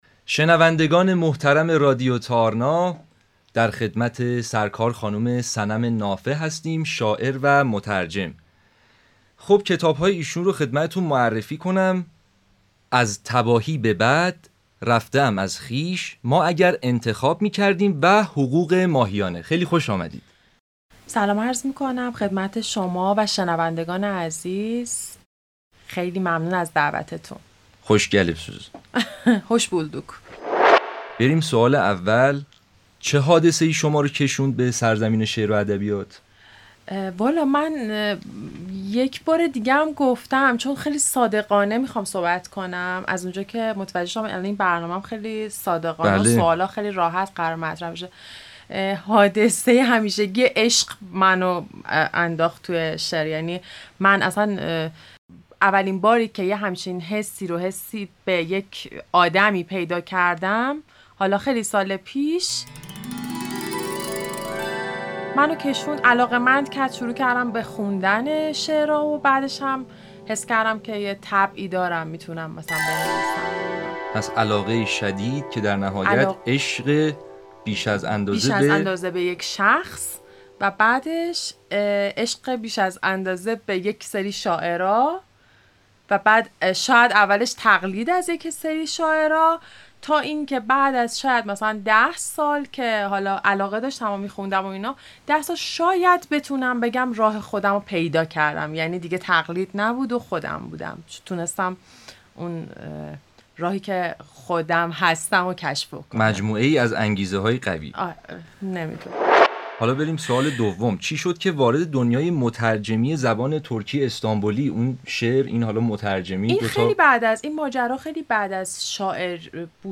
گفت و گو
3 ژانر : برنامه چالشی ادبی گویندگان